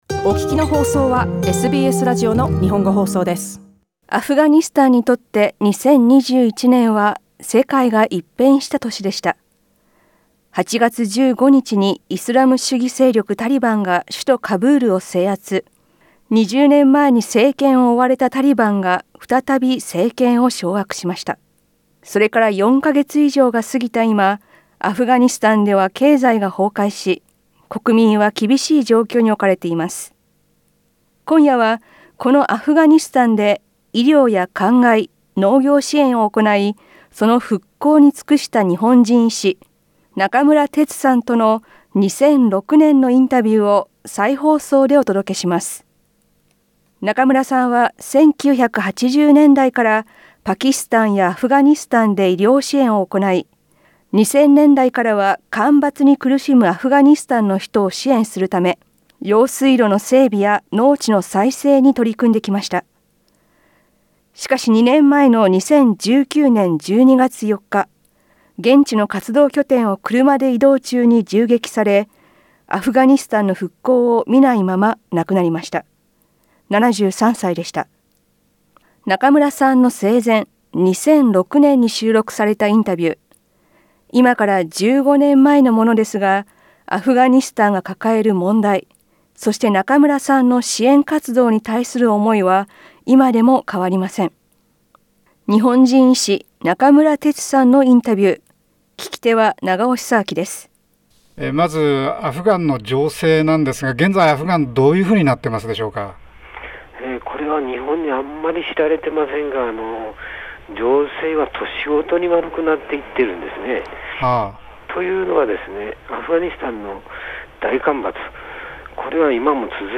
生前の2006年に収録したインタビューです。